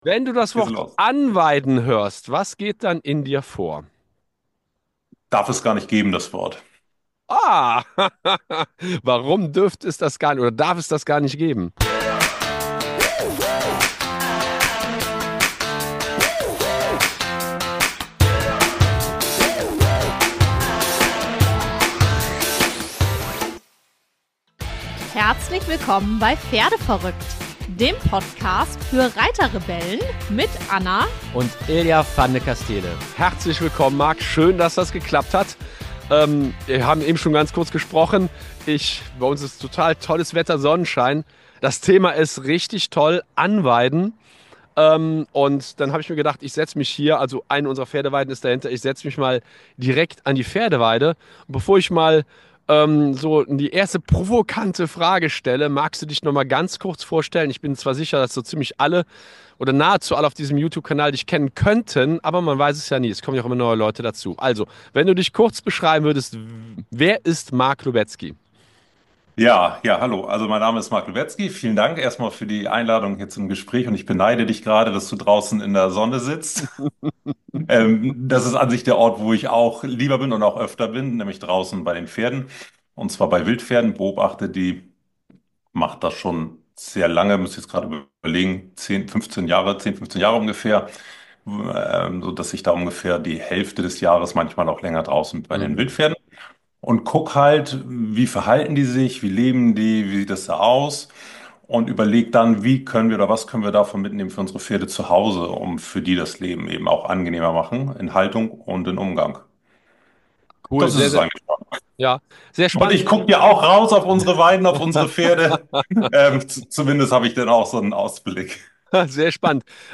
Was provokant klingt erscheint völlig selbstverständlich, wenn er im Interview erklärt, wie Pferde sich in der Natur ernähren.